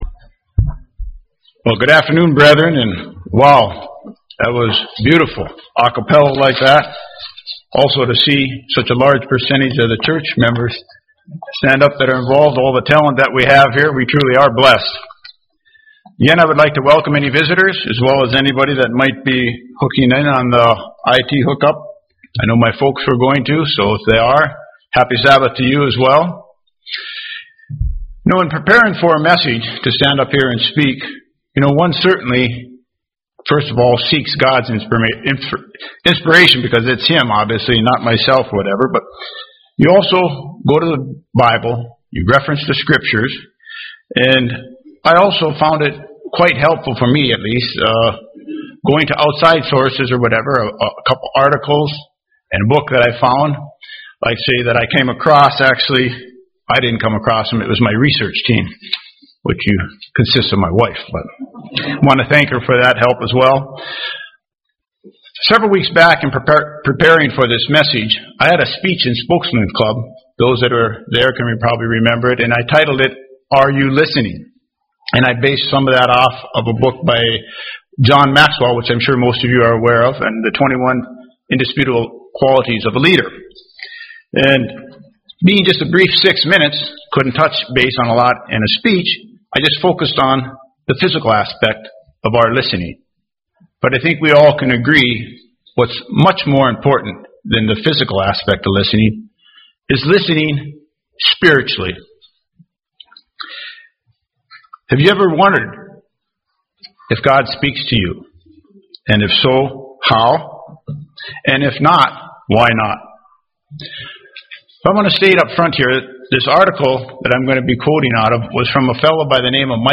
"Spiritual hearing" is a vital part of the relationship with God. How do we have a circumcised ear and hear God in order to honor and obey him? This split-sermon poses the question and offers some observations and ideas.